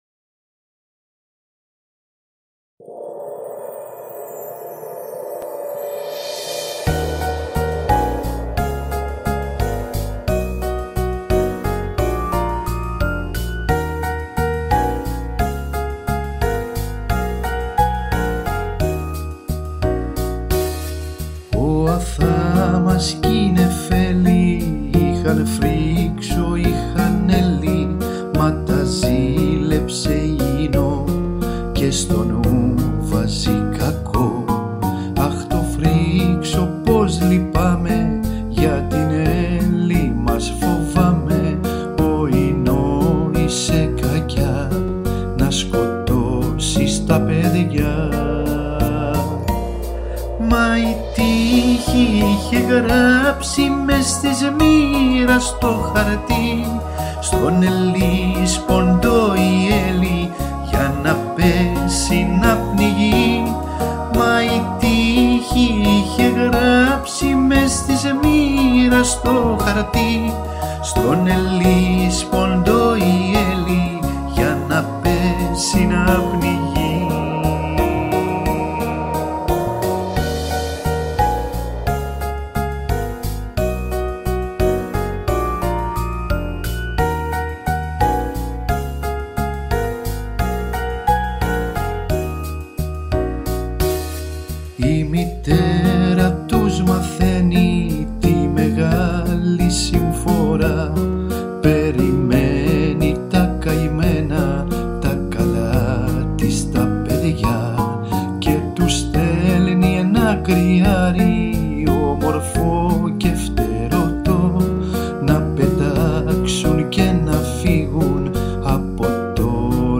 ΤΡΑΓΟΥΔΙ